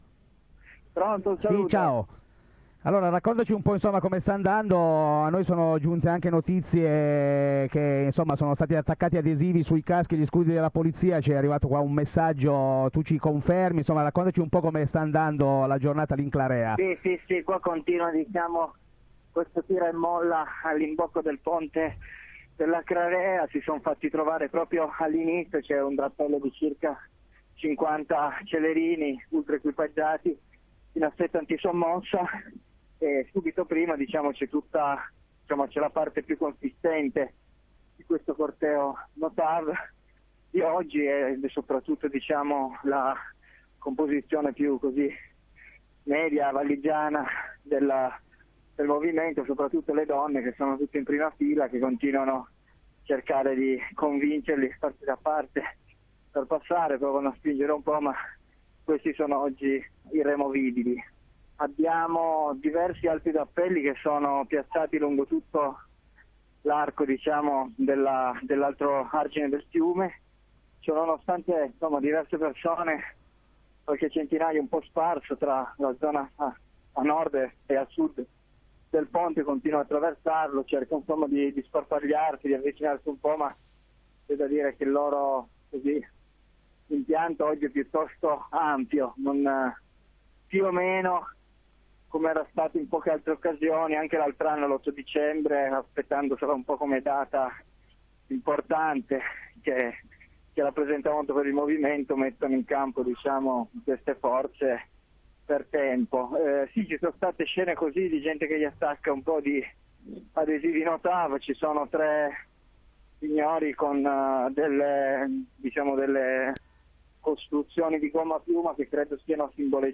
–Dirette–